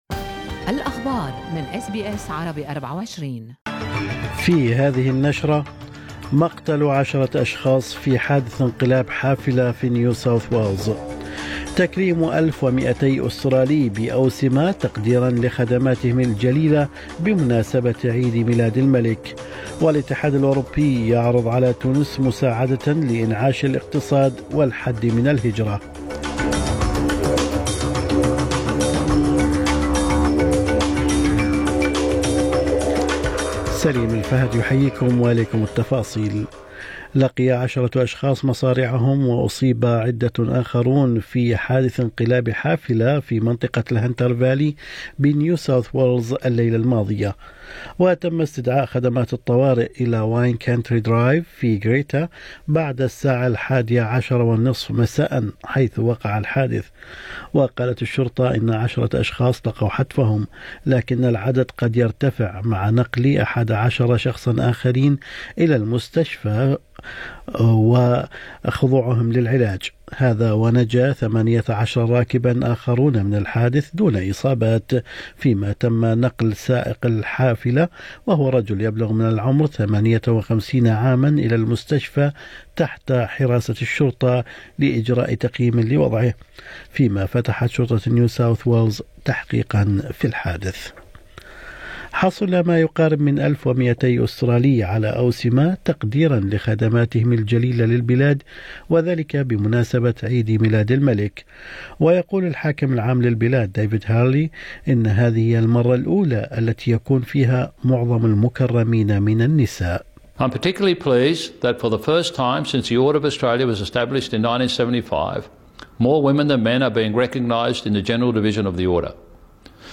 نشرة أخبار الصباح 12/6/2023